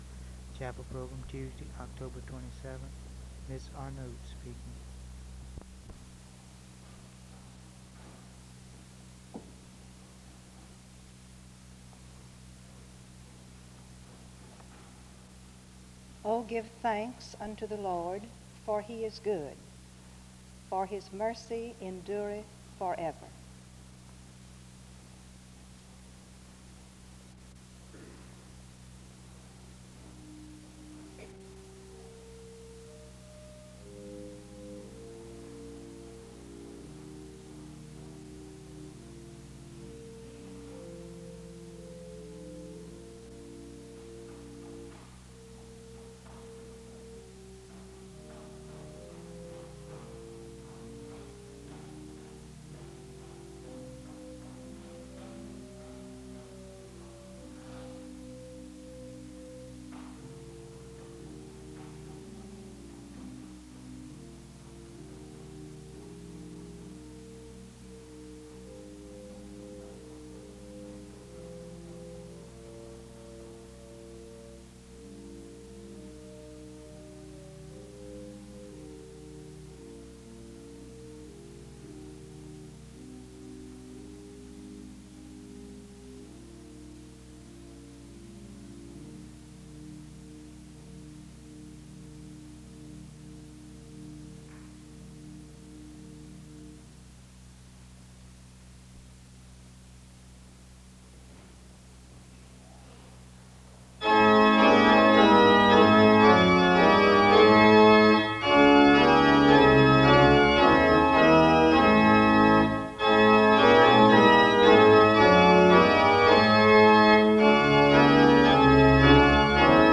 There is a scripture reading and music from 0:15-4:37. A responsive reading is read from 4:38-6:25.
Music plays from 15:12-19:05. A closing prayer is offered from 19:43-19:50.
SEBTS Chapel and Special Event Recordings SEBTS Chapel and Special Event Recordings